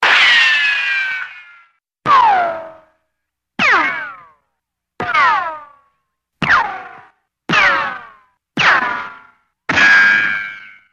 Свист пролетающей пули